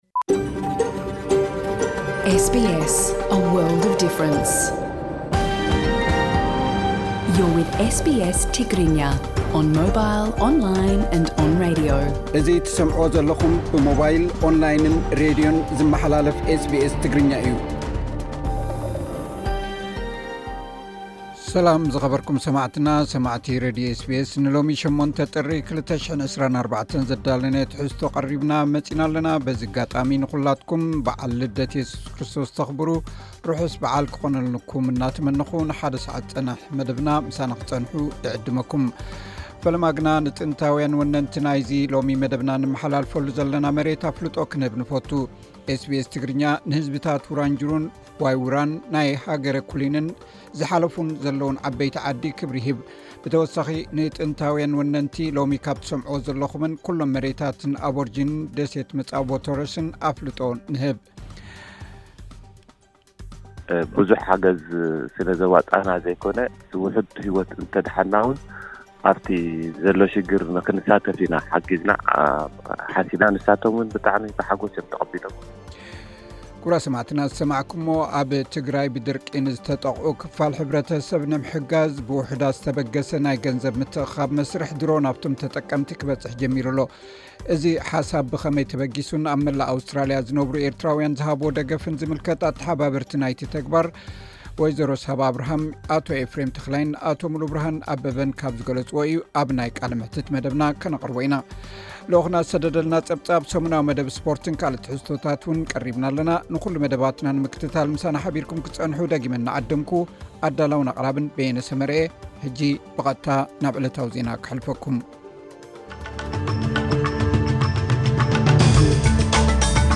ቀጥታ ምሉእ ትሕዝቶ ኤስ ቢ ኤስ ትግርኛ (8 ጥሪ 2024)